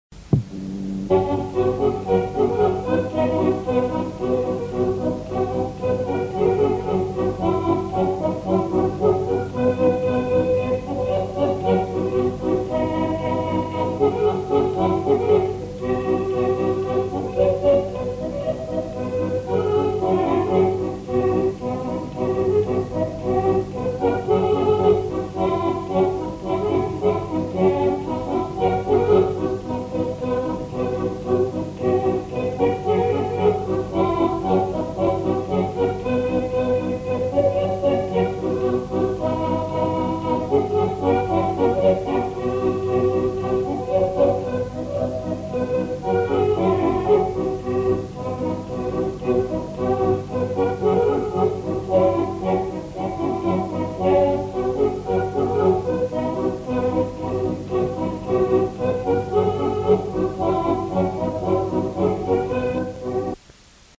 Aire culturelle : Haut-Agenais
Lieu : Monclar d'Agenais
Genre : morceau instrumental
Instrument de musique : accordéon diatonique
Danse : polka